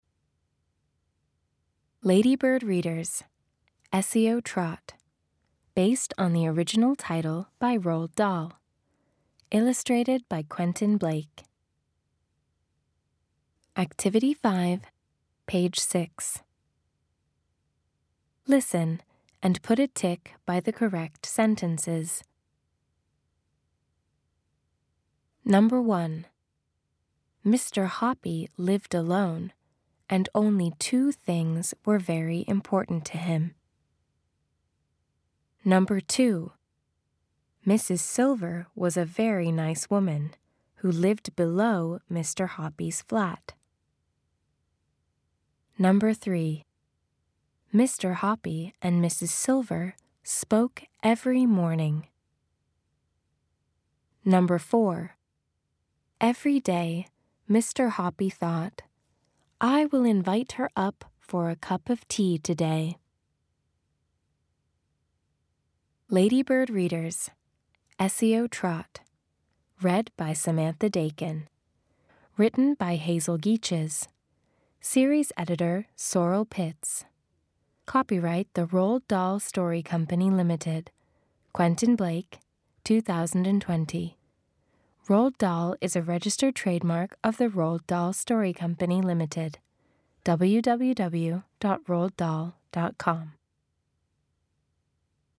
Audio US